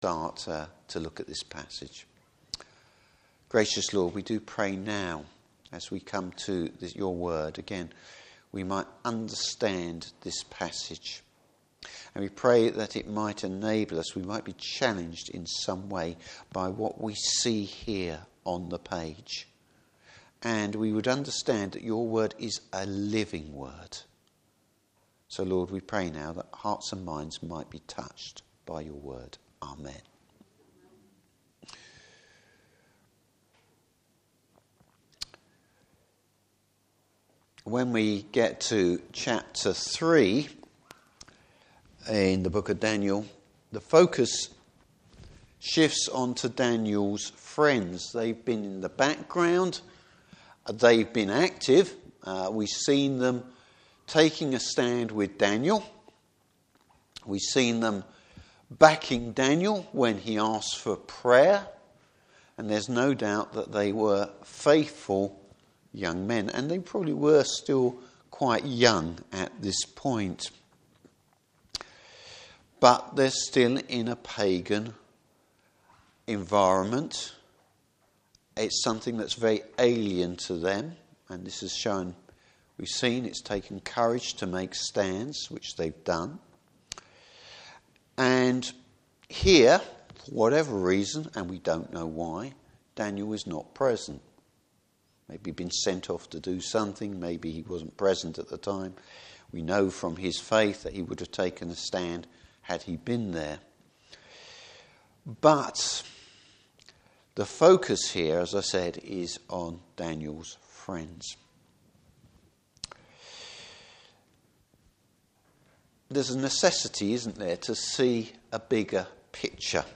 Service Type: Evening Service Trusting in God whatever the outcome.